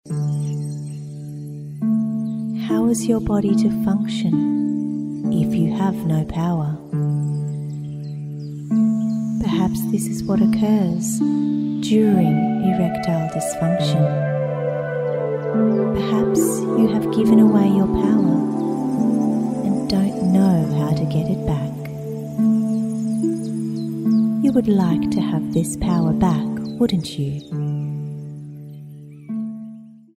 Self-Hypnosis for Erectile Dysfunction